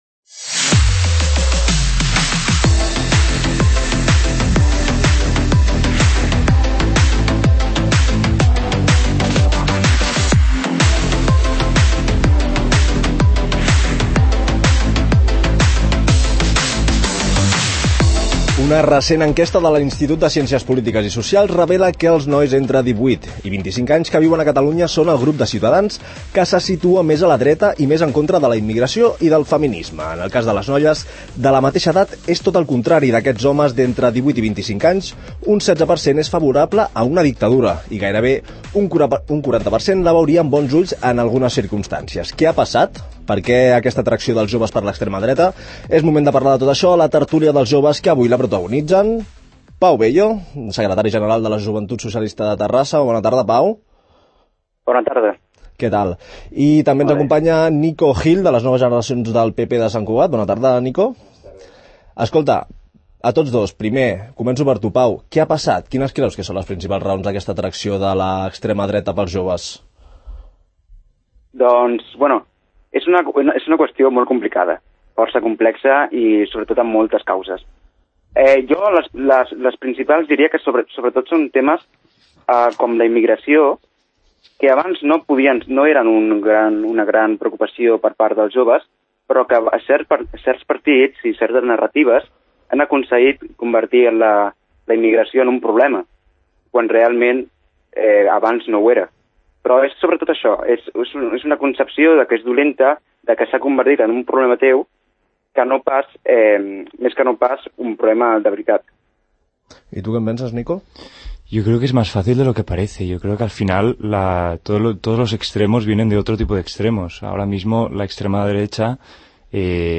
Els joves prenen la paraula a la tertúlia que, com cada darrer divendres de mes, el programa ‘Connectats’ ofereix sobre un tema que els afecta.